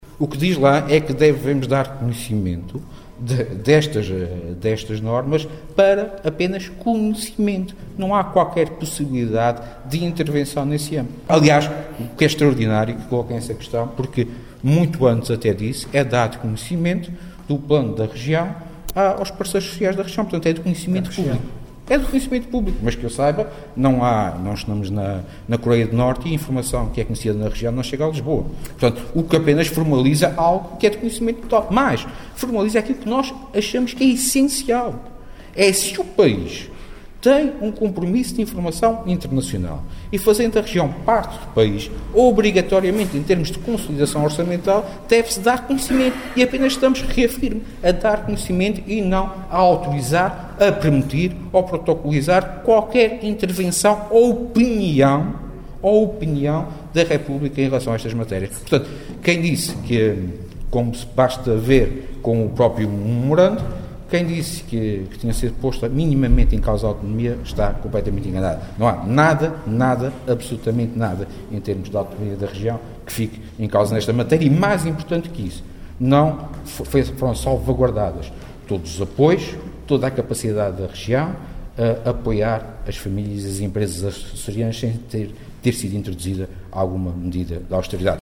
Em declarações aos jornalistas, após da audição na Comissão de Economia, o governante reafirmou que “não há nada, absolutamente nada, em termos de autonomia da Região, que fique em causa, nesta matéria. Mais importante ainda, foi salvaguardada toda a capacidade da Região de apoiar as famílias e as empresas açorianas, sem ser introduzida alguma medida de austeridade”.